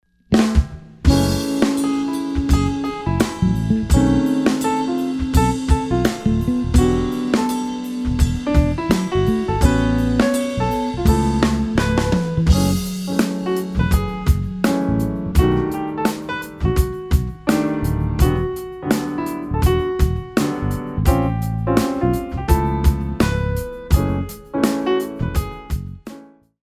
4 bar intro
moderato
Jazz-Funk